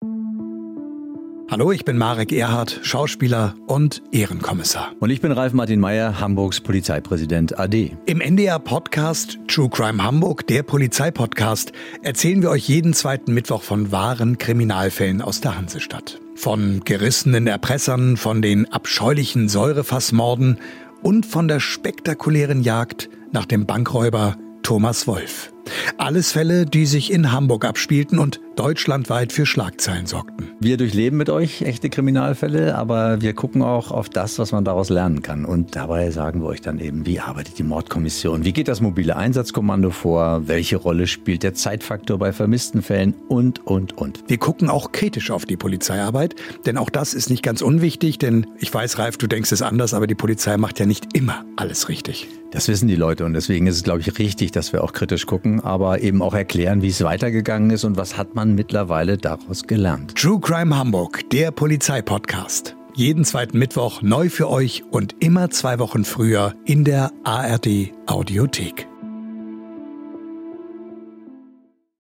Trailer: True Crime Hamburg